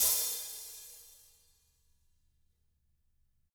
Index of /90_sSampleCDs/ILIO - Double Platinum Drums 1/CD2/Partition D/THIN A HATR